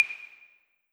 tap_card.wav